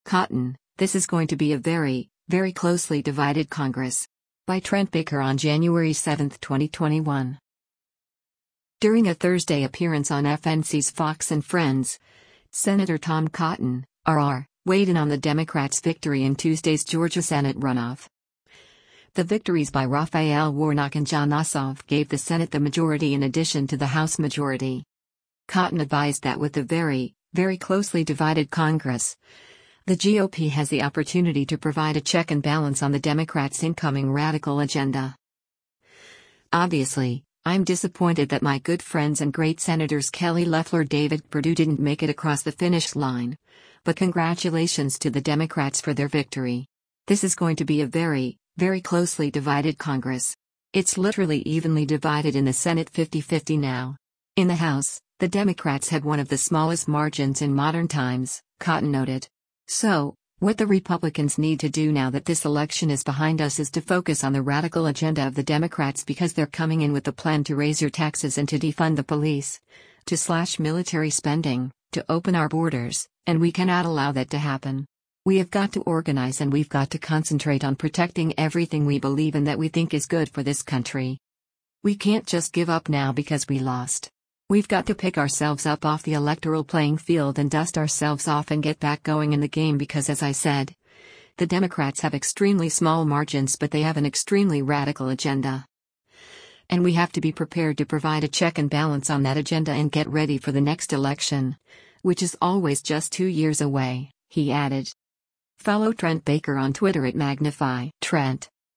During a Thursday appearance on FNC’s “Fox & Friends,” Sen. Tom Cotton (R-AR) weighed in on the Democrats’ victory in Tuesday’s Georgia Senate runoff.